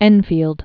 (ĕnfēld)